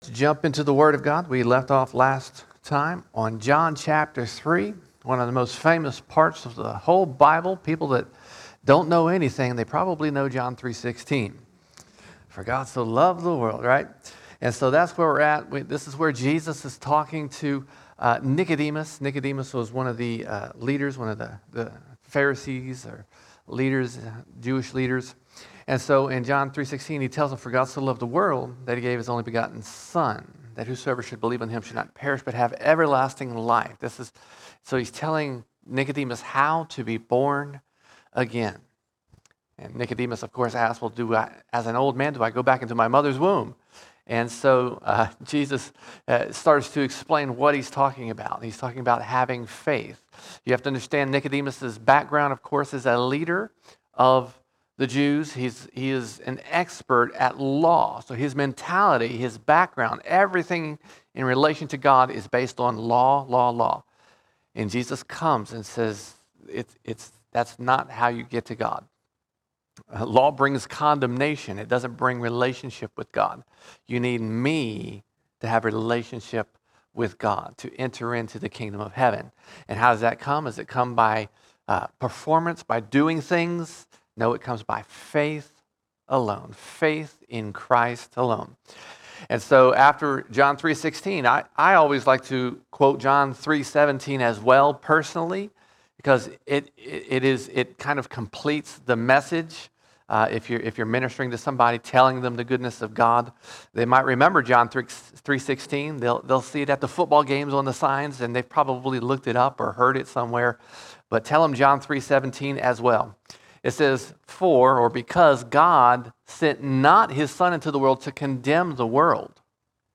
25 August 2022 Series: John All Sermons John 3:17 to 3:34 John 3:17 to 3:34 Jesus came not to condemn but to save.